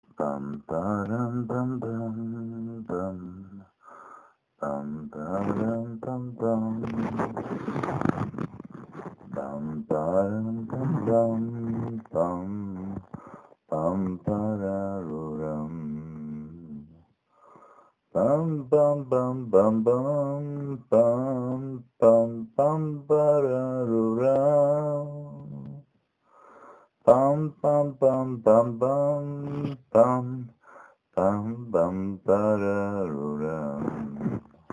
В файле я намычал примерную мелодию